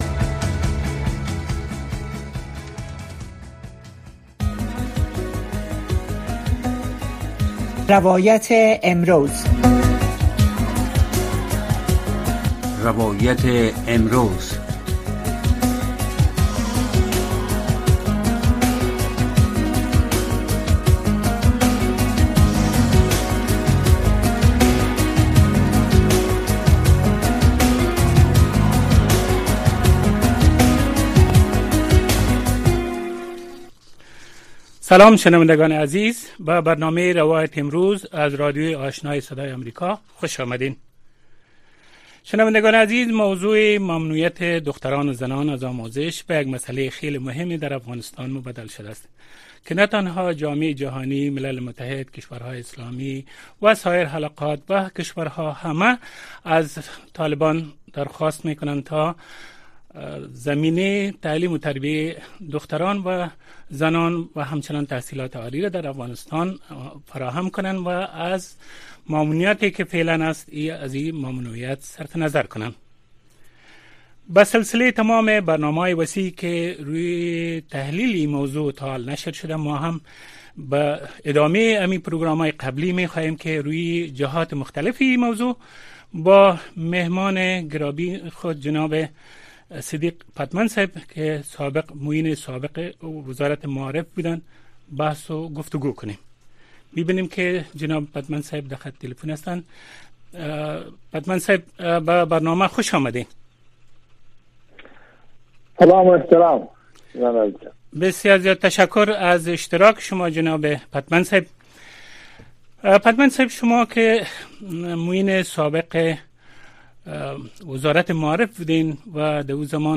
در برنامۀ روایت امروز شرح وضعیت در افغانستان را از زبان شهروندان و شرکت کنندگان این برنامه می‌شنوید. این برنامه هر شب از ساعت ٩:۳۰ تا ۱۰:۰۰ شب به گونۀ زنده صدای شما را پخش می‌کند.